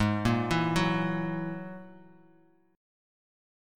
G#7sus2#5 chord